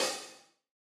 OpenHH Zion 1.wav